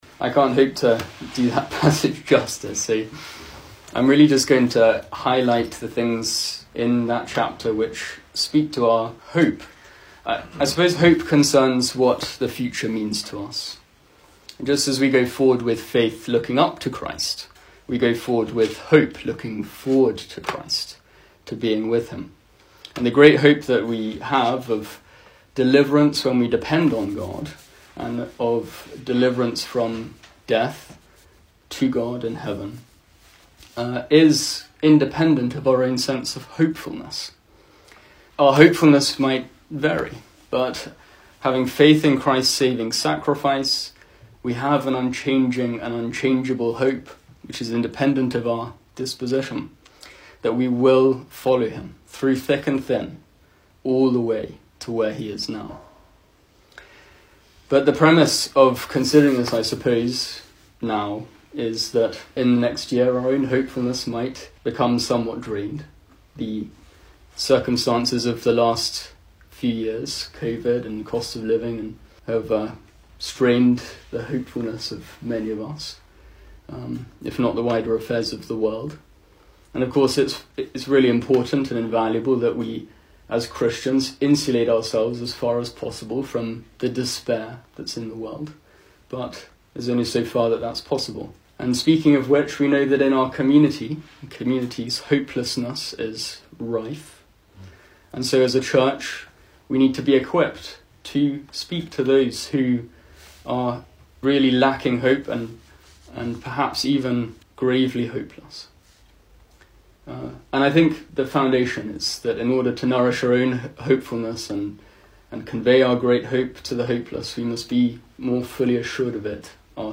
Service Type: Weekday Evening